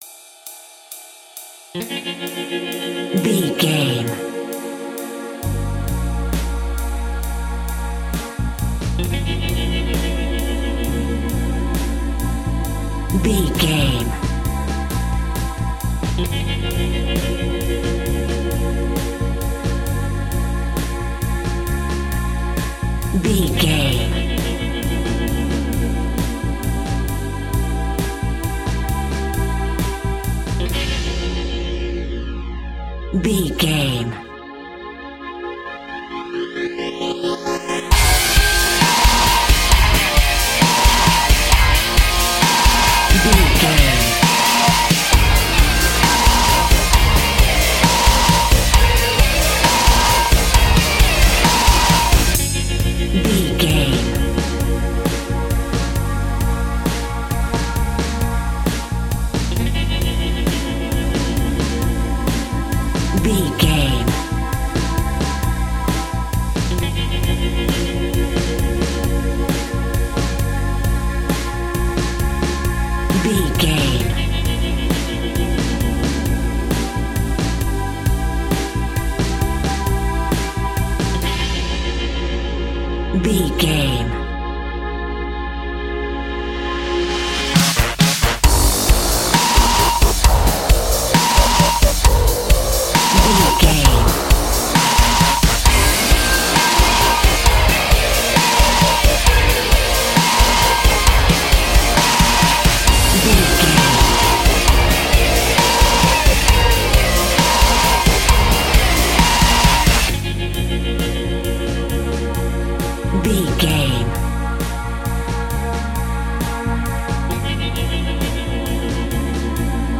Aeolian/Minor
B♭
drums
synthesiser
electric guitar
pop rock
hard rock
metal
angry
lead guitar
bass
aggressive
energetic
intense
powerful
nu metal
alternative metal